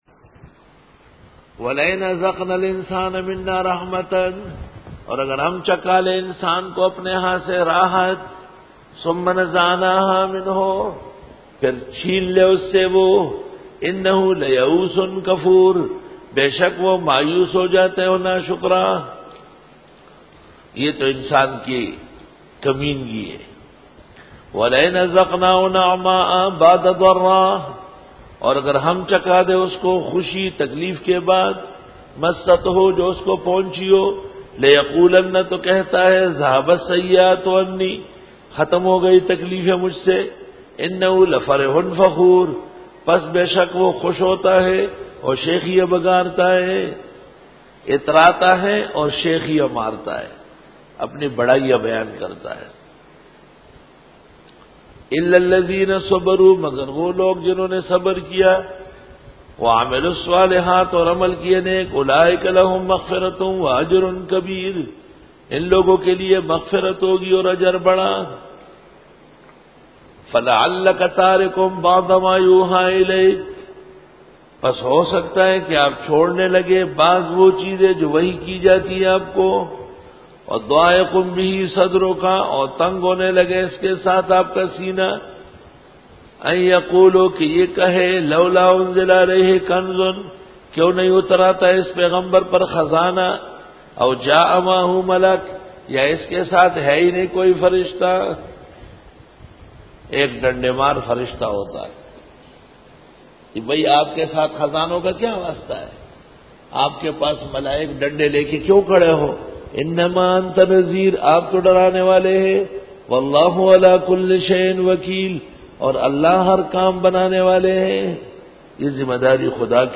سورۃ ھودرکوع-02 Bayan